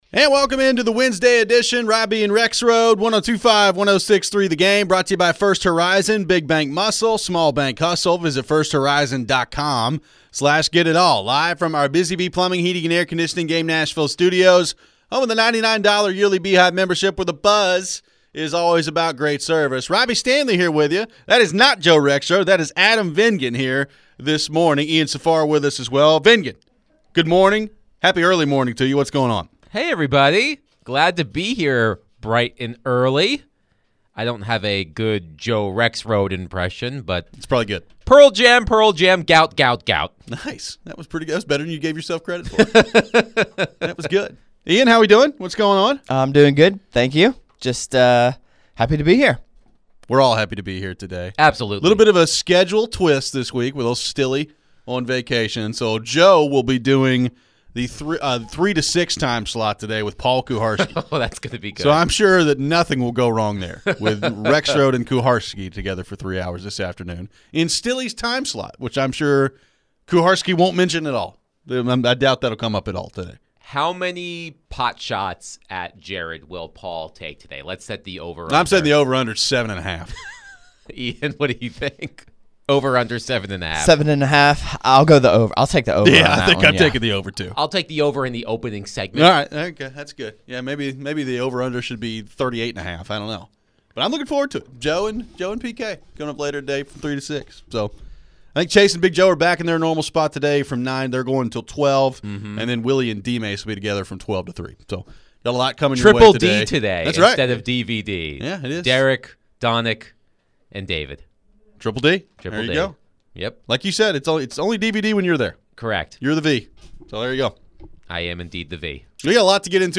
We talk more about the front office structure of the Titans and can they all mesh to turn things around? We take your phones.